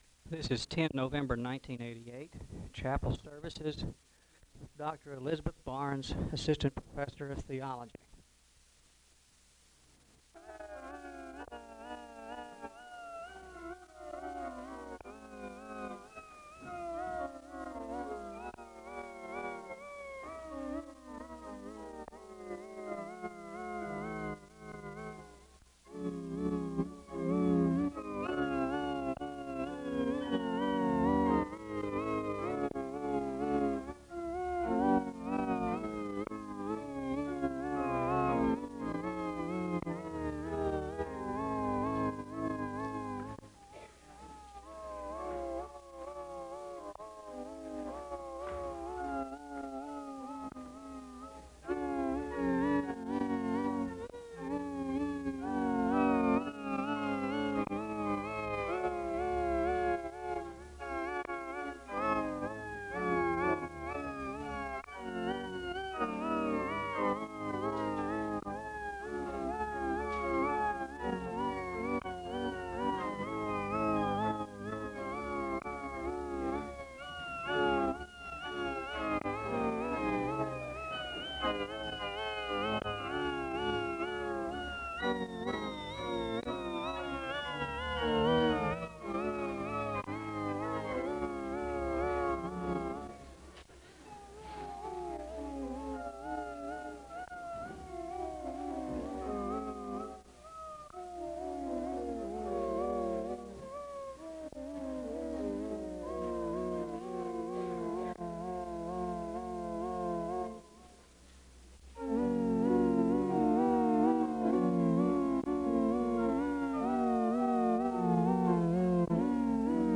A hymn is played (0:09-4:18). The choir sings a song of worship (4:19-7:09). A hymn is played (cut) followed by a word of prayer (7:10-8:51).
A soloist sings (10:31-15:57).
The choir sings another song of worship (32:47-36:39).